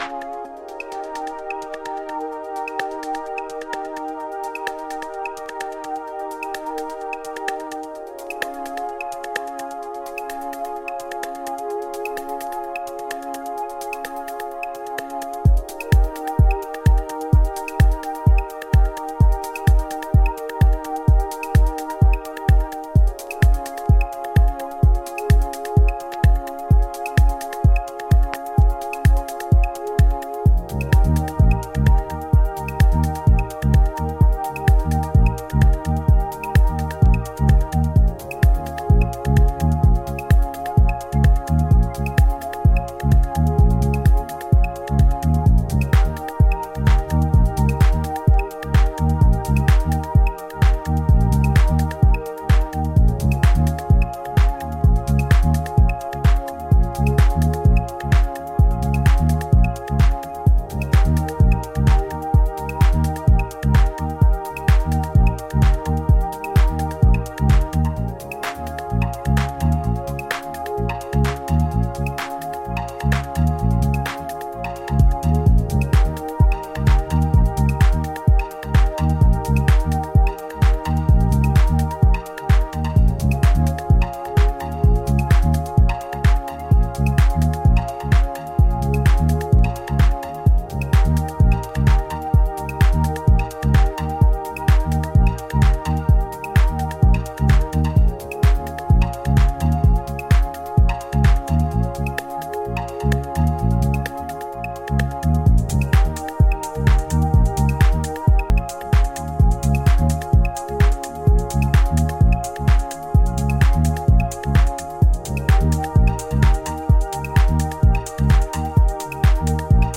Three dreamy house and electro cuts
Electro House